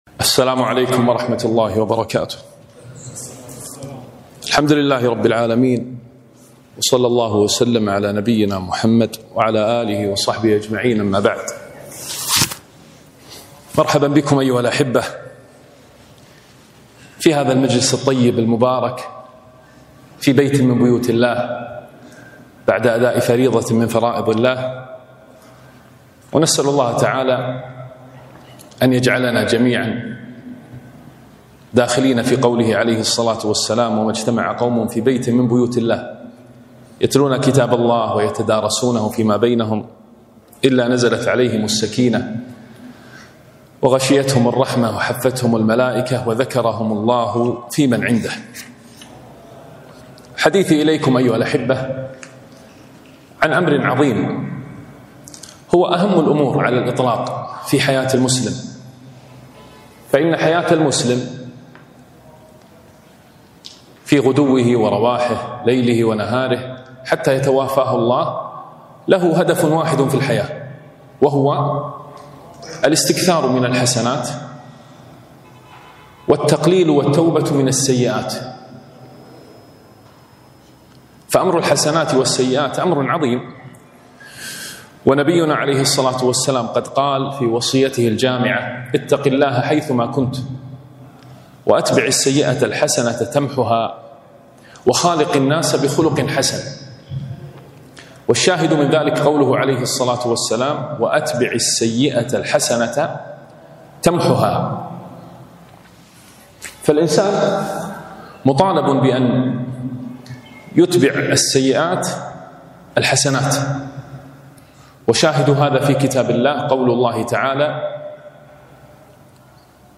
كلمة - الحسنات الجارية